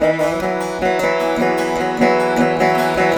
151  VEENA.wav